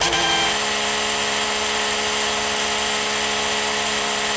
rr3-assets/files/.depot/audio/sfx/transmission_whine/trans_off_high.wav
trans_off_high.wav